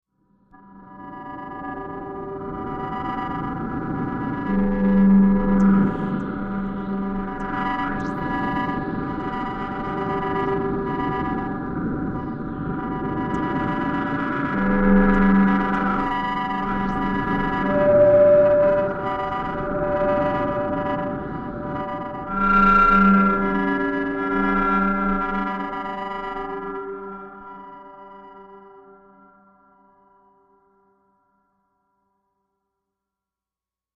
Wandering Satellite Lost Space Probe Calling Transmission